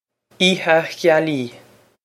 Pronunciation for how to say
ee-ha ghyal-ee
This is an approximate phonetic pronunciation of the phrase.